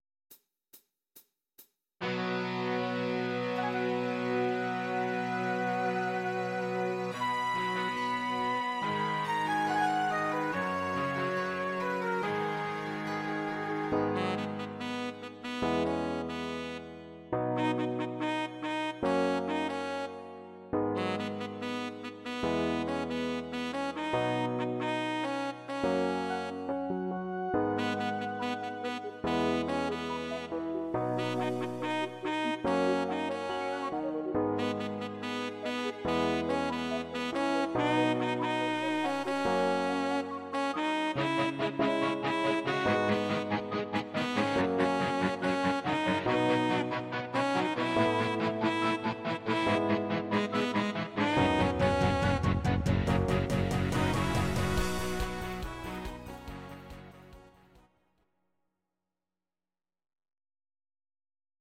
These are MP3 versions of our MIDI file catalogue.
Your-Mix: Rock (2970)